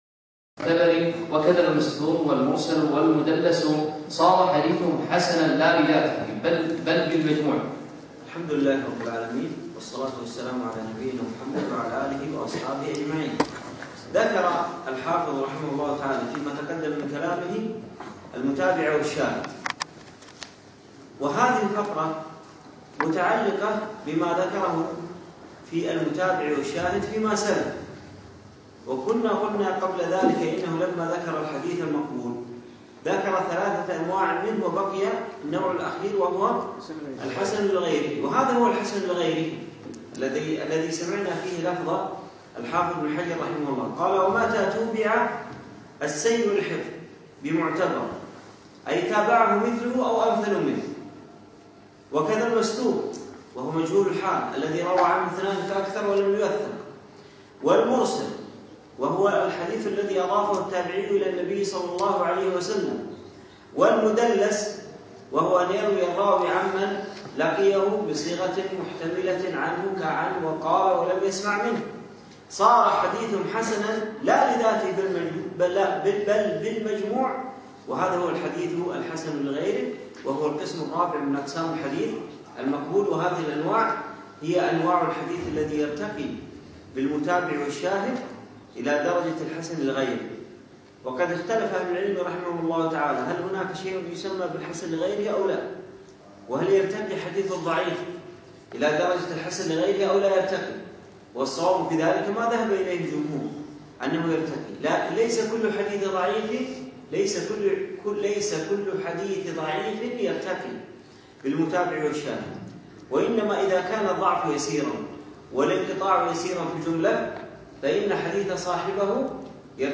يوم السبت 7 جمادى أول 1438 الموافق 4 2 2017 في مسجد زين العابدين سعد ال عبدالله
الدرس السابع